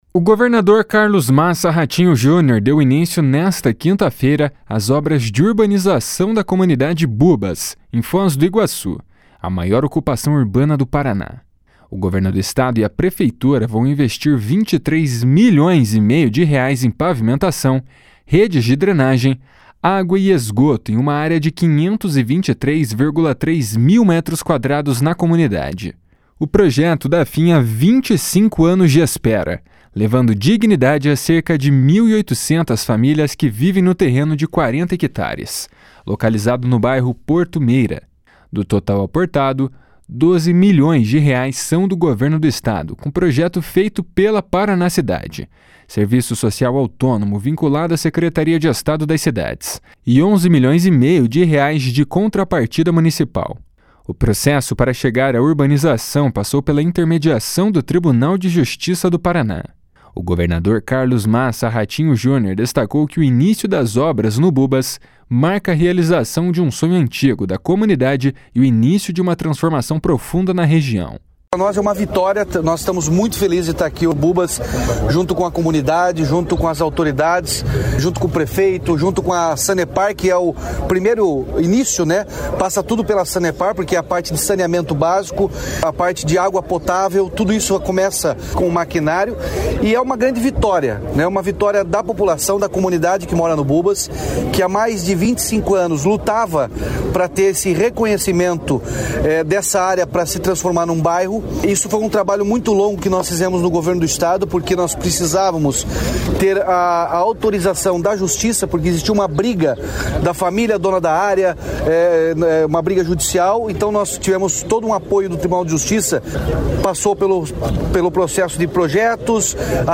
O governador Carlos Massa Ratinho Junior destacou que o início das obras no Bubas marca a realização de um sonho antigo da comunidade e o início de uma transformação profunda na região.
O prefeito de Foz do Iguaçu, Joaquim Silva e Luna, destacou o simbolismo do momento para as famílias que vivem no Bubas.
O secretário estadual das Cidades, Guto Silva, afirmou que o projeto representa cidadania e justiça social.